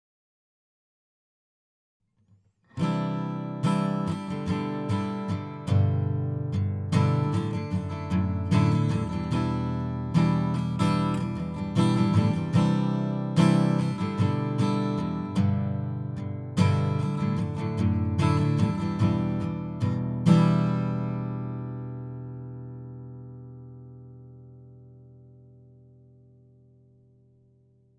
Demo Audio realizzate presso Trees Music Studio di Cava de’Tirreni
Chitarra Acustica D.I.- Focusrite Red 8Pre
Chitarra Acustica – Larrivee